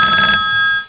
ringin.wav